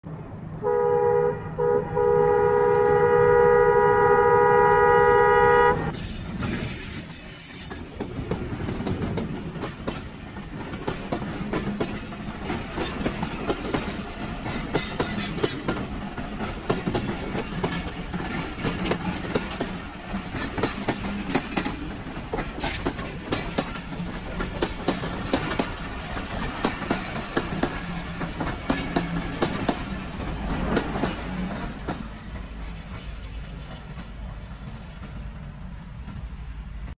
Arrival Of Fareed Express 38dn Sound Effects Free Download